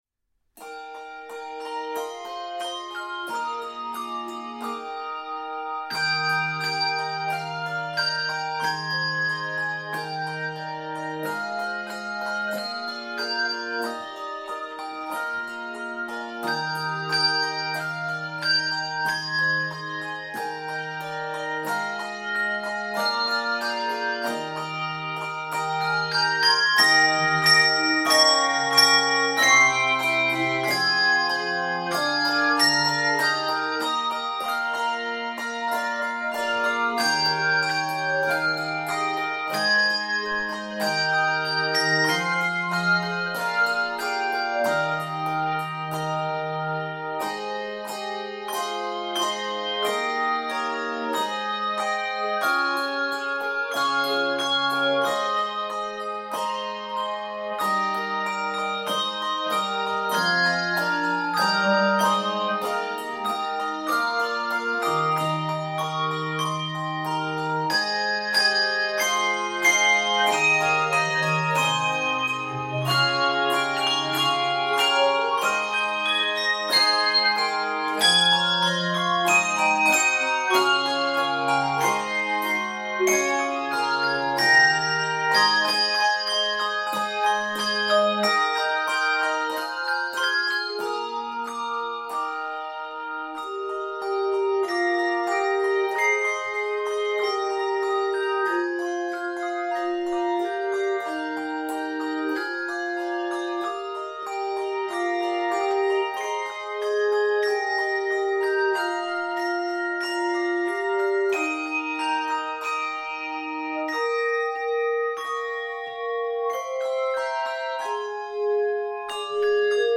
a flowing medley of two wonderful worship hymns